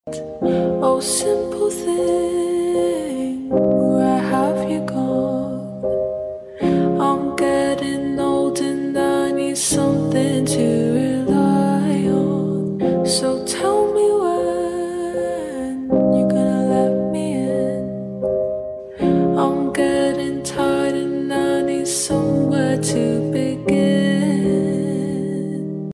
American Airlines 737 Max 9 Sound Effects Free Download